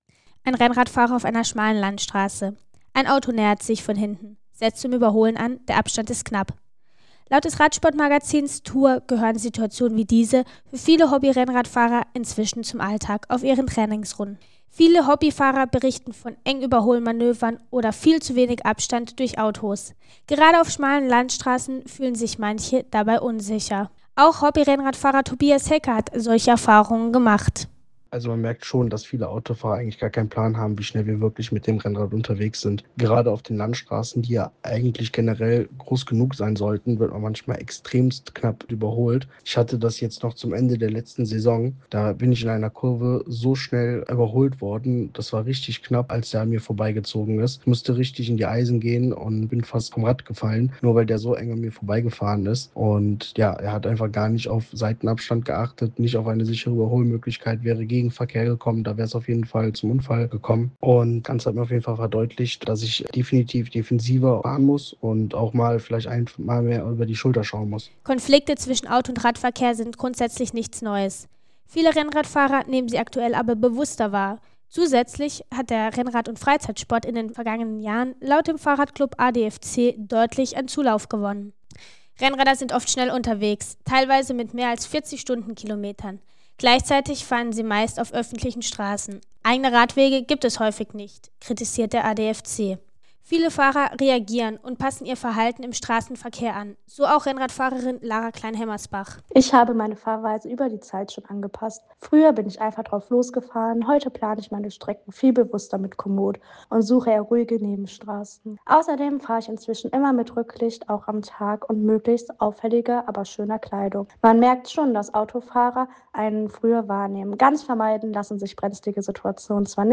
Der-Renner-Radiobeitrag-Übung.mp3